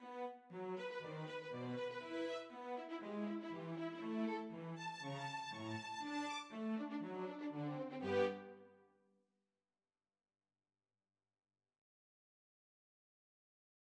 Ровное движение баса на фоне последовательных повторов «мягкой и заговорщической»[5] фразы формирует гармоническую последовательность по кругу квинт: